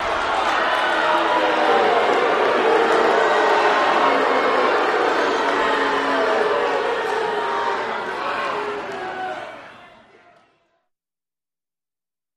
Crowd Boos, Yeahs During Fight, Conflicting L and R